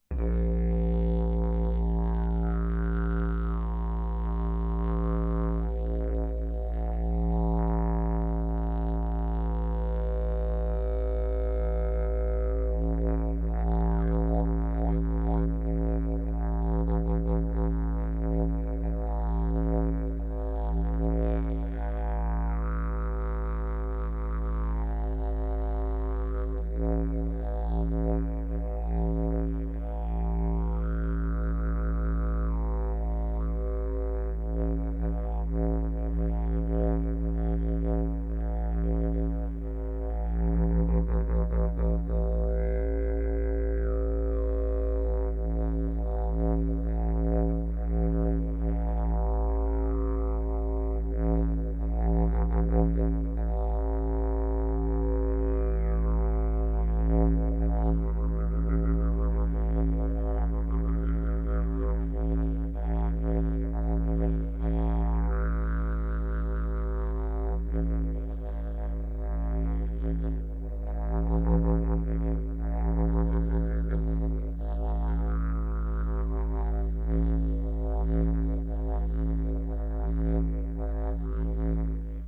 didjeridoo